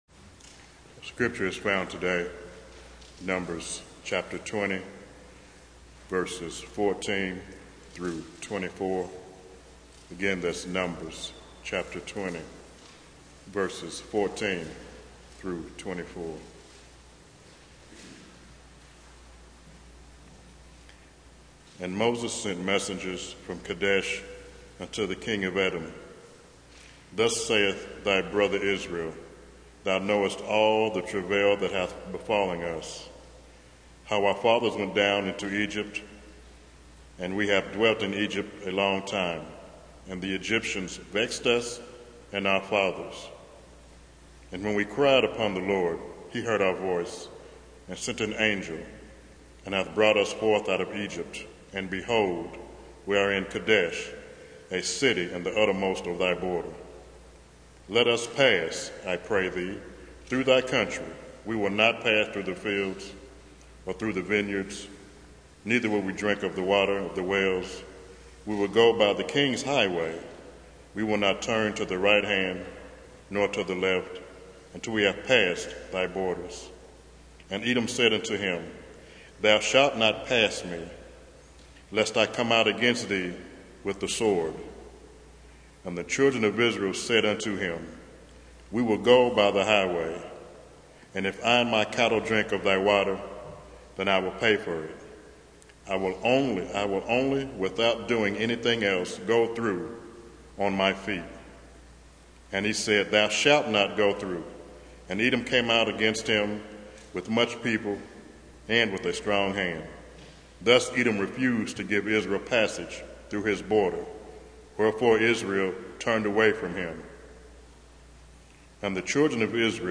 Series: Roadblocks in the Wilderness Service Type: Sunday Morning